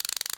ratchetloop.ogg